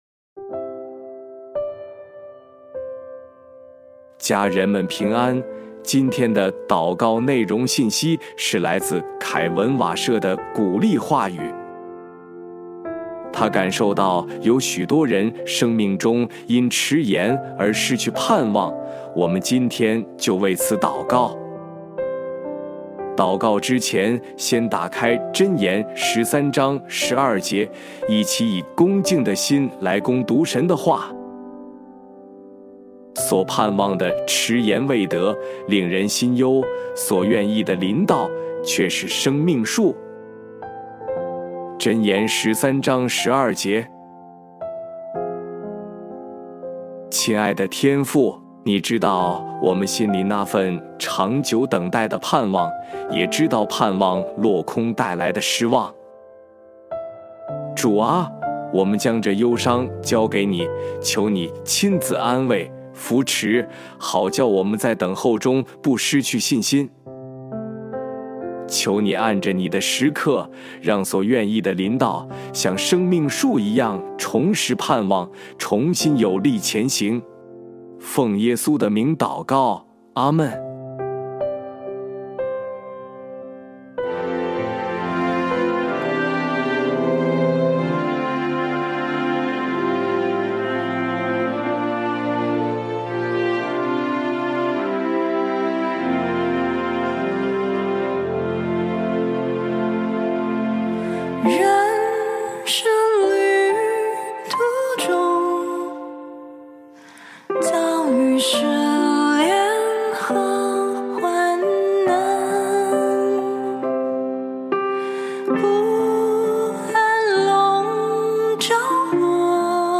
本篇是由拉娜瓦舍授权，由微牧之歌翻译撰稿祷告及朗读 凭着信心重拾盼望 第一音源 第二音源 家人们…